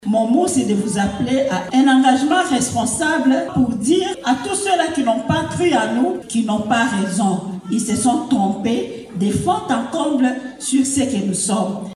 Suivez un extrait du discours d’Irene Esambo.